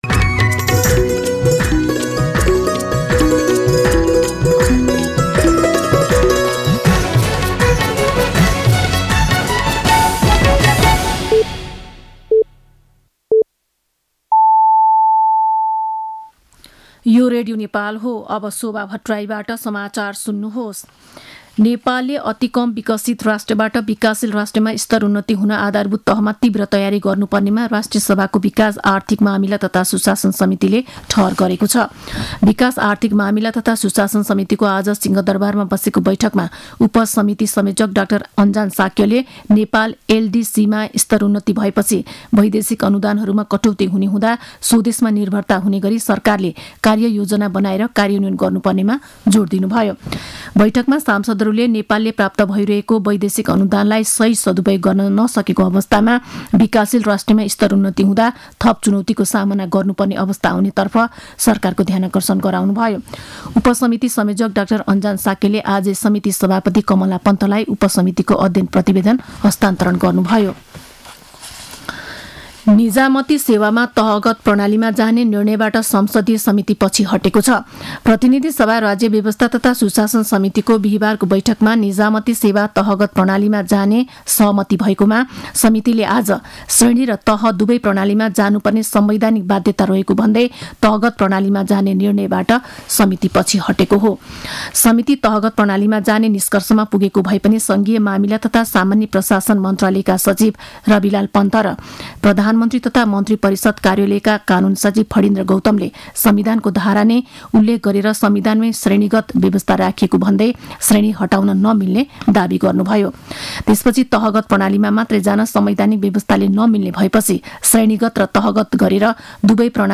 दिउँसो ४ बजेको नेपाली समाचार : ३ फागुन , २०८१
4-pm-Nepali-News-1.mp3